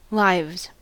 Uttal
Uttal US US UK: IPA : /ˈlɪvz/ IPA : /ˈlaɪvz/ US: IPA : /ˈlɪvz/ IPA : /ˈlaɪvz/ Ordet hittades på dessa språk: engelska Ingen översättning hittades i den valda målspråket.